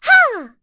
Dosarta-Vox_Jump.wav